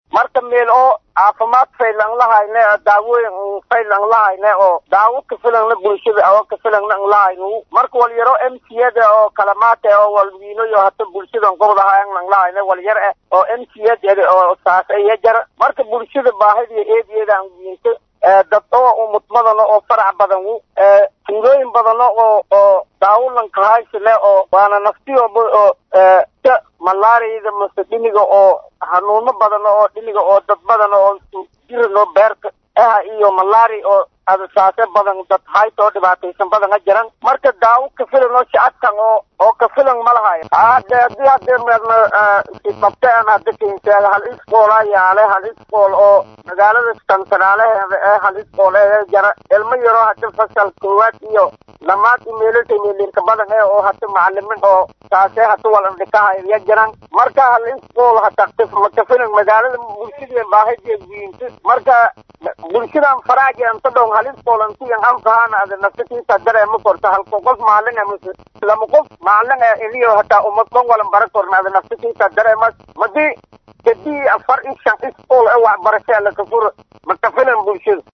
Gudoomiyaha Degaankaasi Guufgaduud Shabellow Axmed Jadiid Oo Kahadlaayo Xaalada Kajirto Halkaasi.